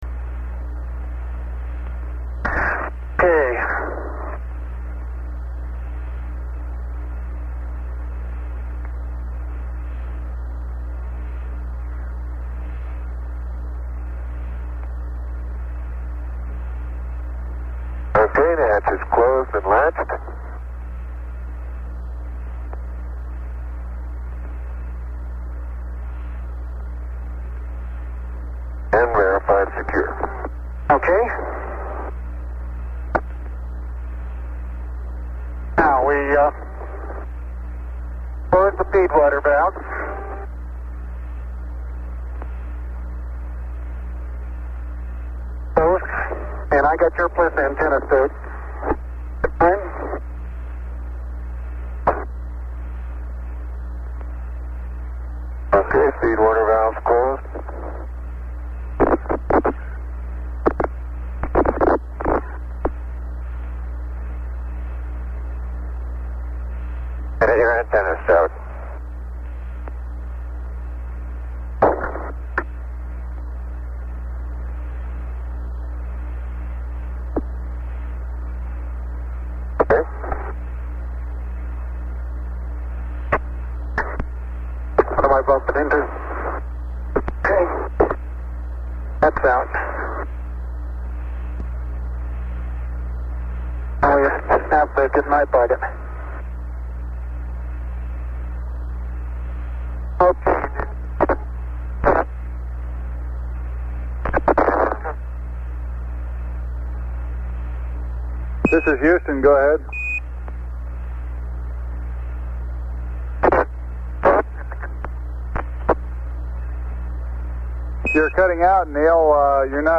Audiodatei (19 min 40 s, MP3-Format, 18 MB) Die Aufnahme der Kommunikation mit dem Raumschiff beginnt bei 110:52:53.